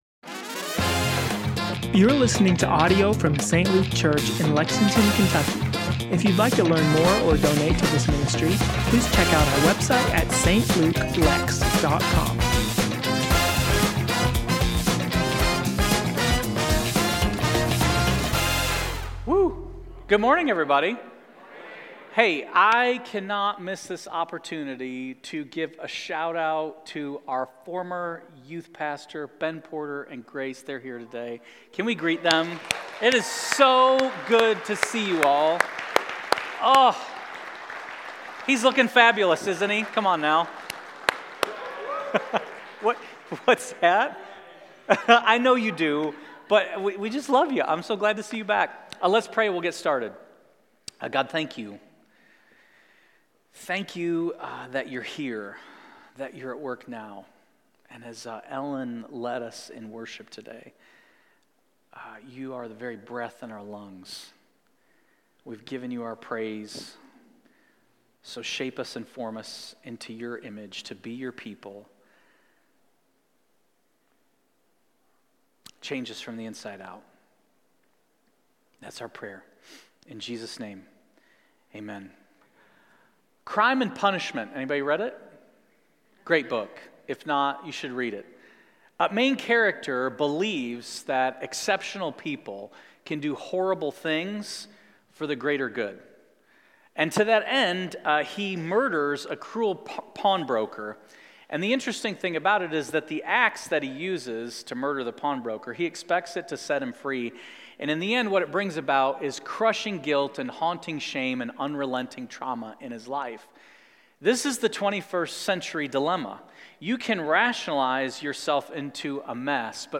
St. Luke Church Lexington – Sermons & Teachings